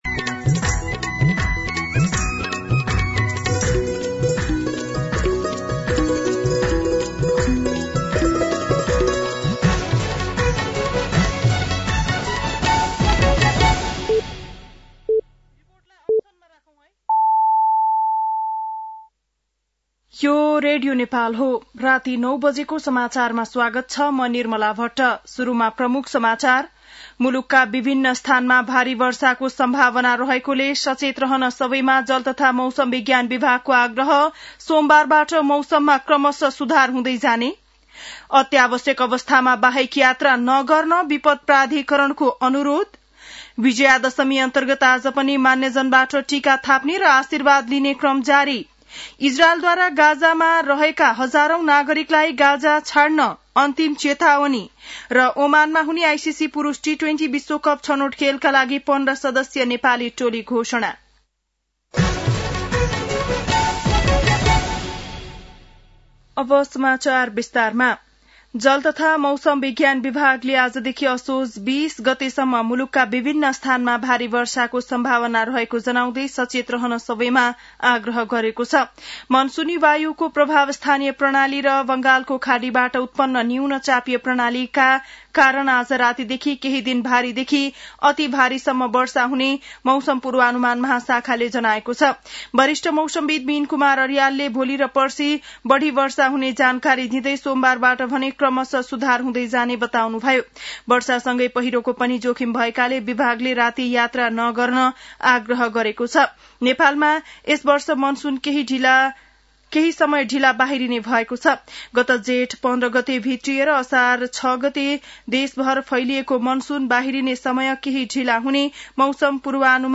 An online outlet of Nepal's national radio broadcaster
बेलुकी ९ बजेको नेपाली समाचार : १७ असोज , २०८२